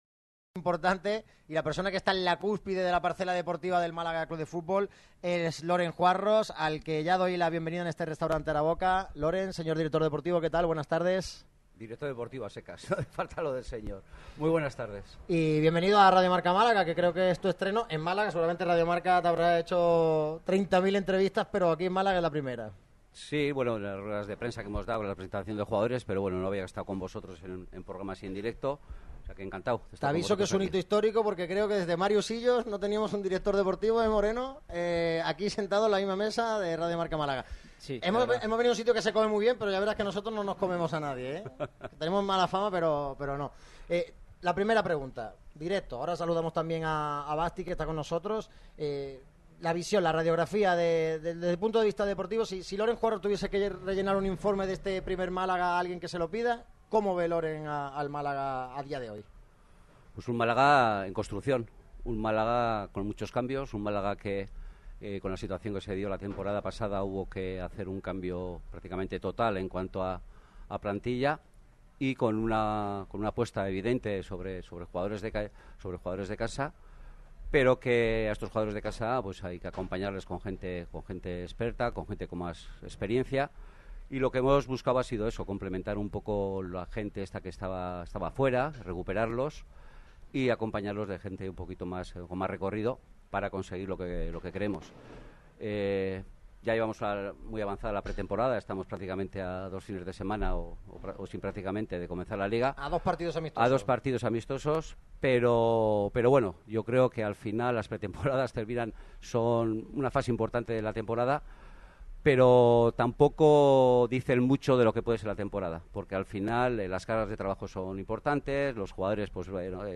La comitiva del Málaga CF atendió en directo a Radio MARCA Málaga